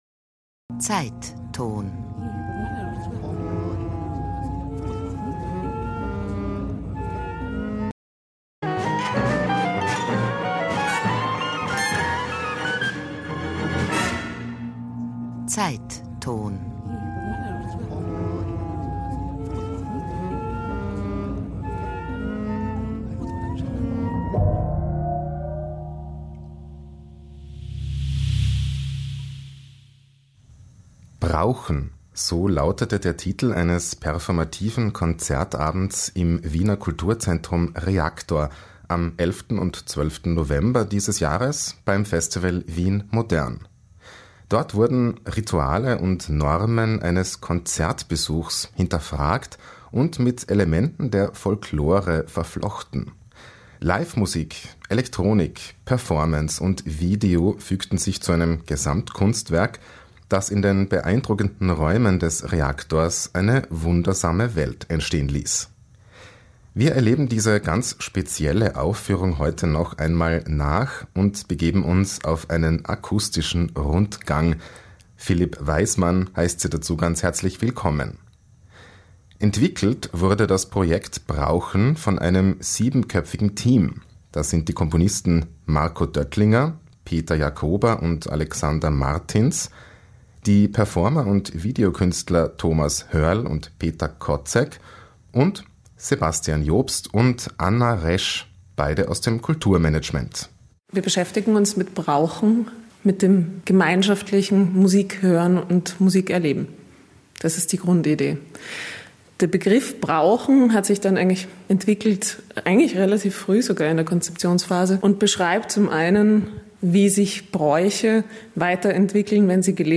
Ein performativer Konzertabend
Cello
Violine
Posaune
Klarinette
Sopran
Klavier, Analogsynthesizer
Tuba
Perkussion
Flöte